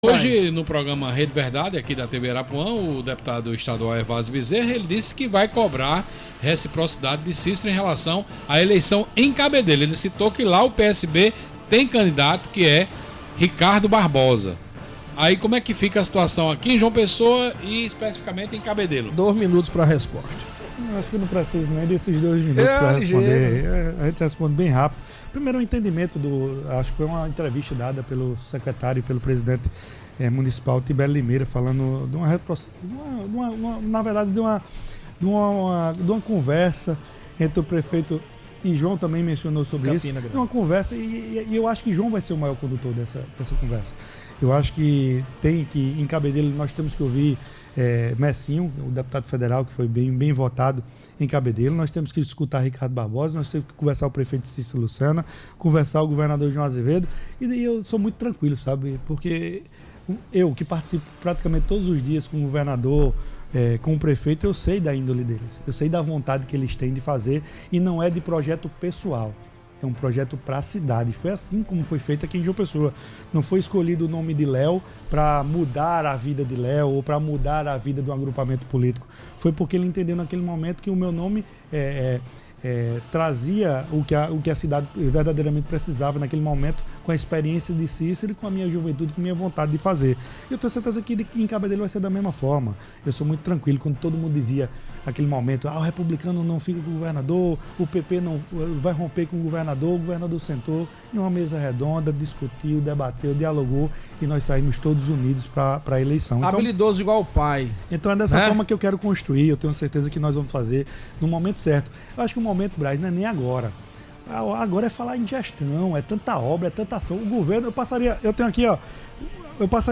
Ele fez essa declaração durante uma entrevista no programa desta segunda-feira (3), ao comentar as declarações do governador João Azevêdo (PSB) e do deputado Hervázio Bezerra, que cobraram reciprocidade do prefeito Cícero Lucena (PP) e do deputado federal Mersinho Lucena (PP) em relação ao apoio à candidatura do PSB em Cabedelo.